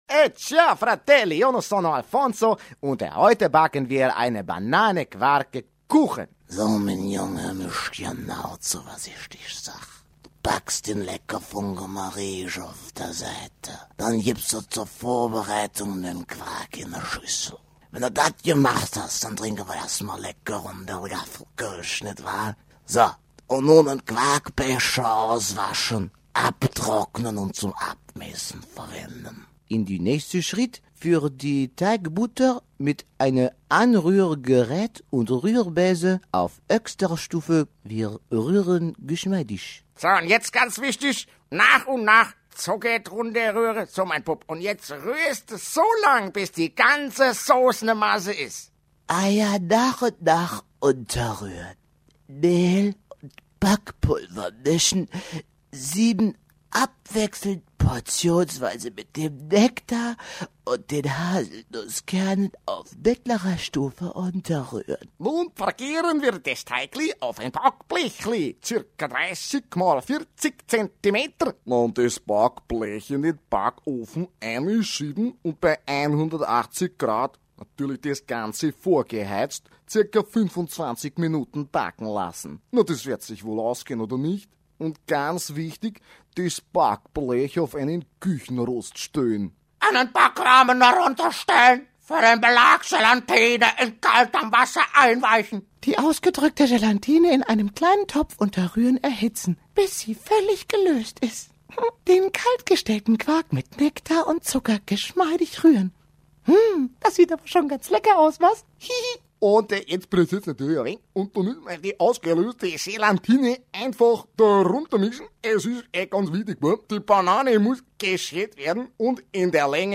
deutscher Profi-Sprecher. TV, Radio, Werbung, Synchron
Kein Dialekt
Sprechprobe: eLearning (Muttersprache):
german voice over artist